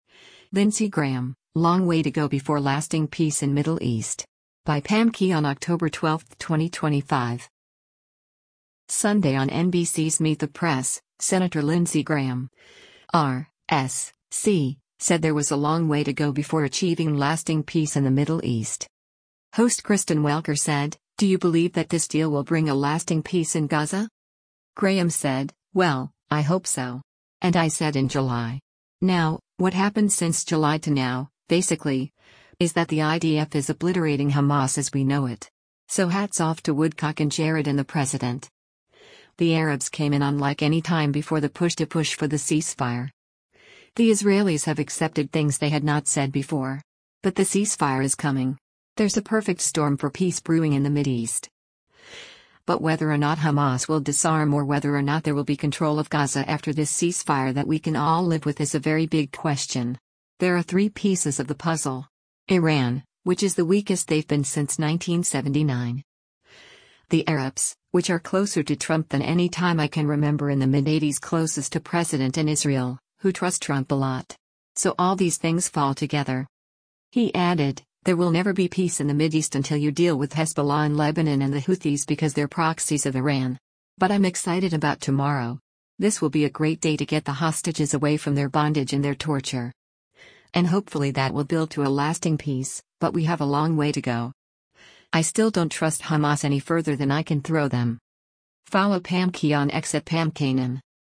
Sunday on NBC’s “Meet the Press,” Sen. Lindsey Graham (R-S.C.) said there was a “long way to go” before achieving lasting peace in the Middle East.